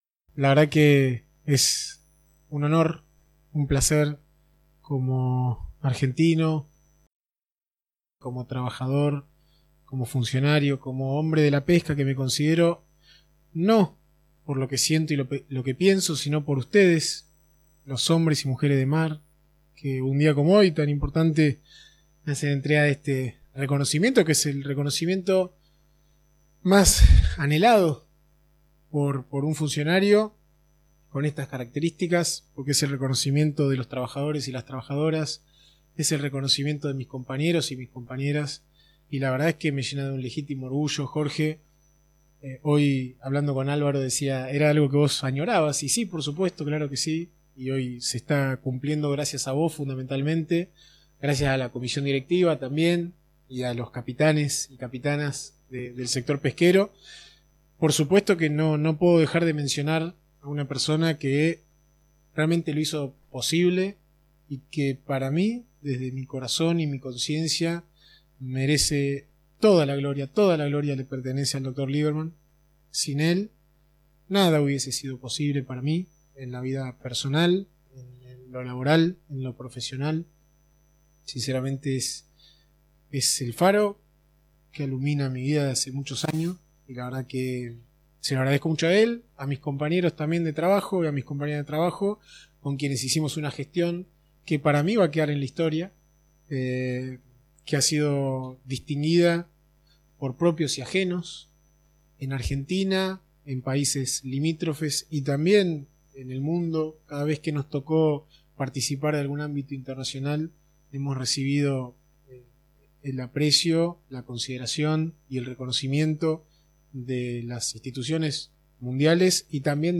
Palabras del Dr. Julián Suárez, tras recibir su "Popa"